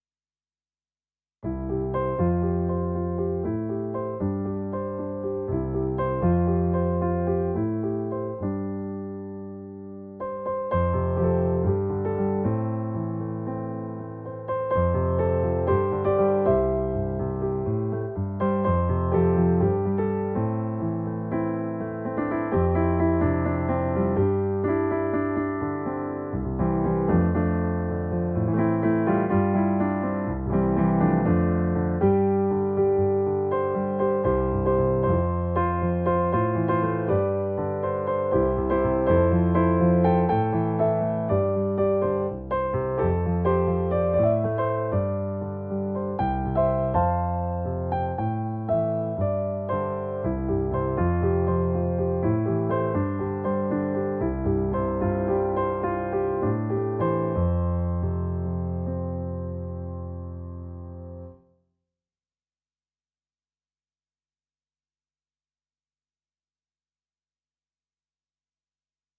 • State-of-the-art ultra-realistic electronic piano sounds